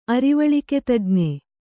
♪ arivaḷiketajñe